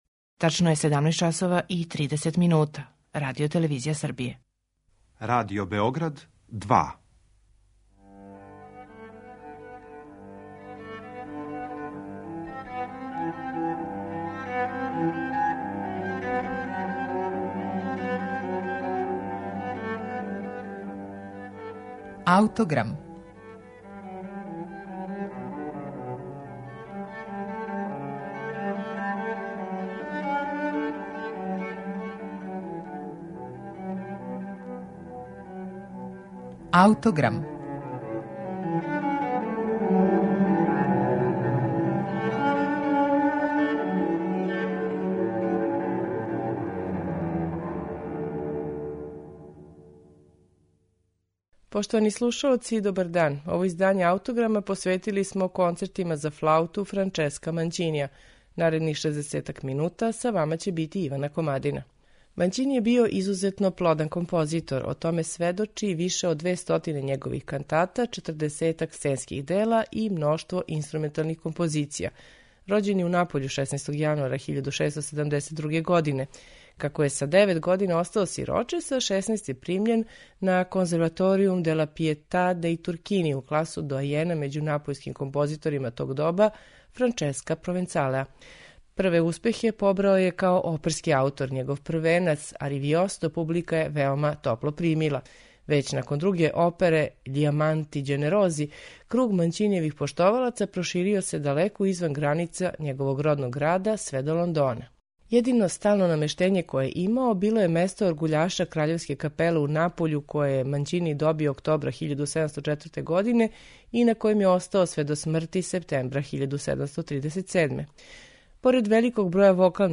Слушаћете их на снимку који су на оригиналним инструментима епохе остварили
уздужна флаута
чембало